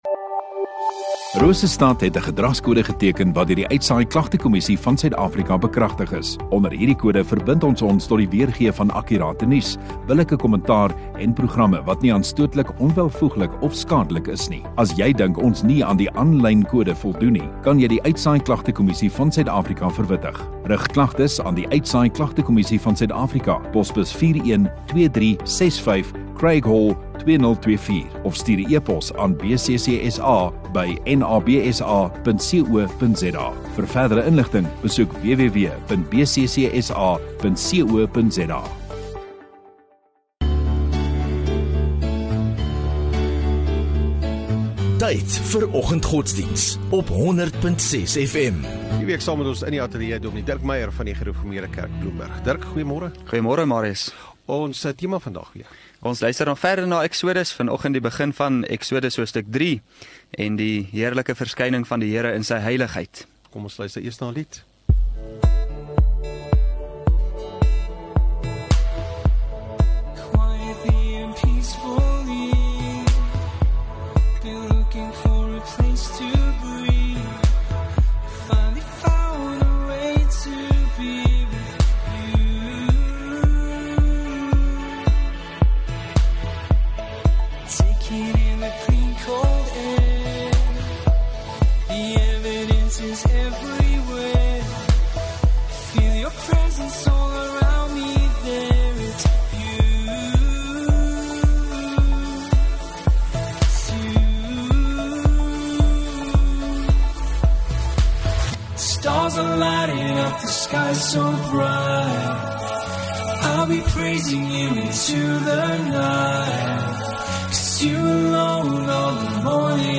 26 Sep Donderdag Oggenddiens